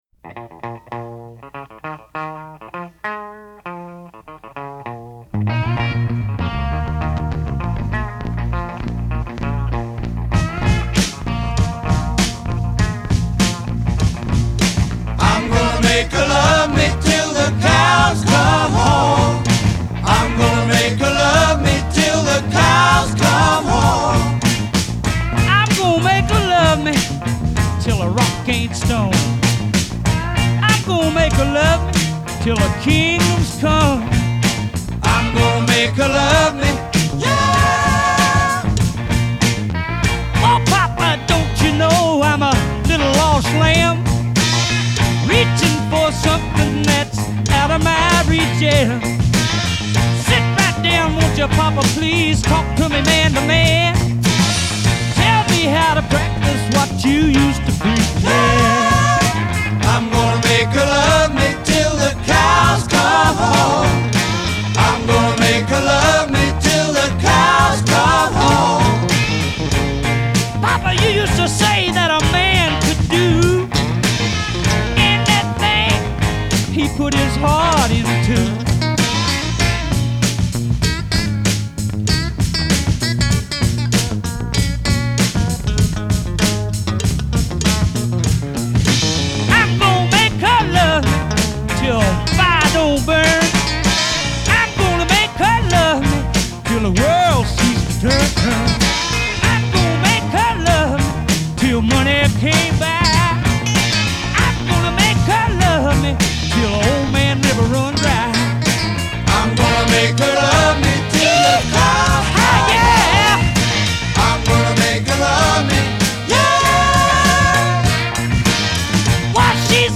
Funky. Soulful.